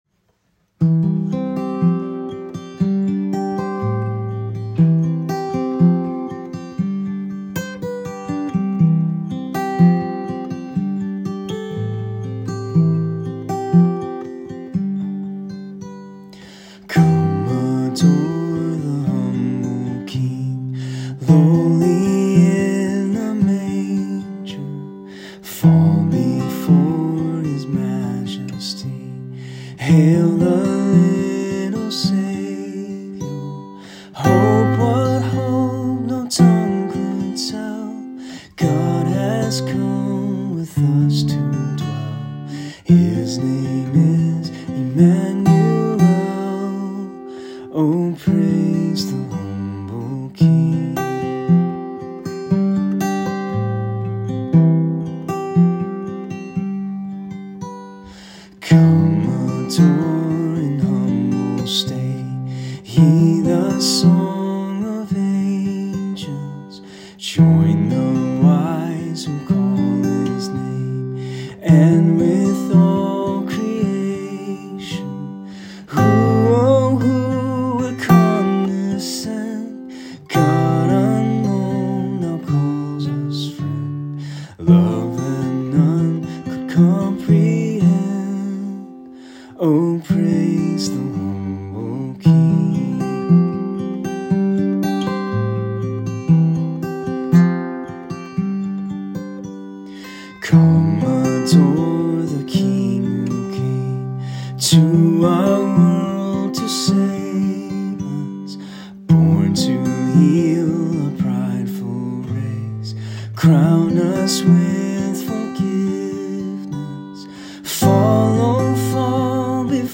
Christmas Celebration 2023 (Kid's Choir Songs)
Kids Choir Songs (audio)
2nd - 5th Grade: